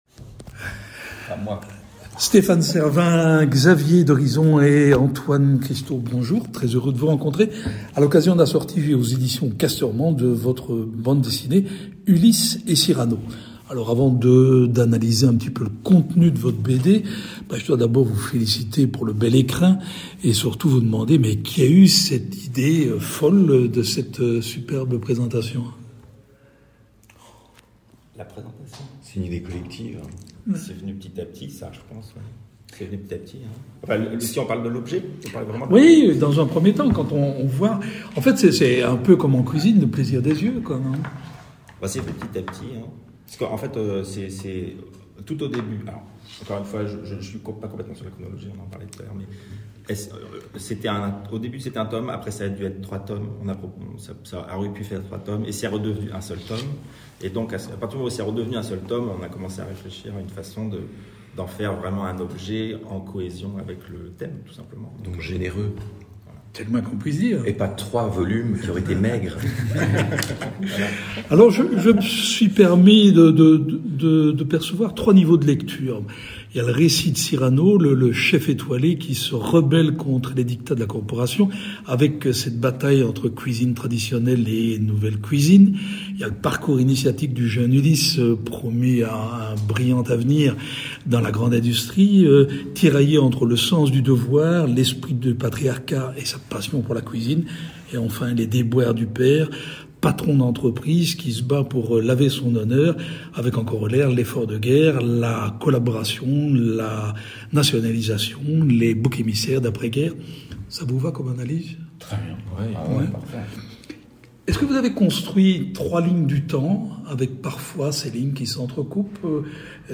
Rencontre avec les auteurs